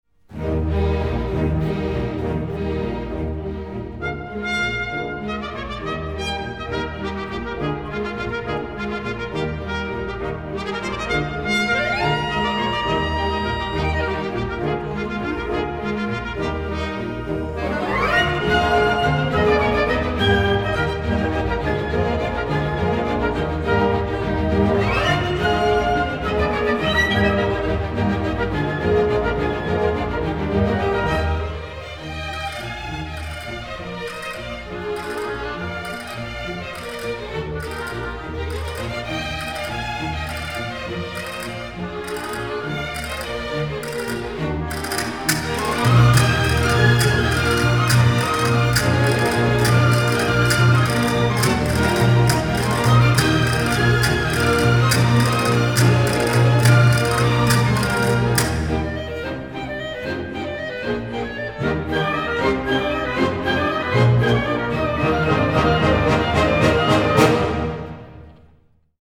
Ballet
Orquesta
Música clásica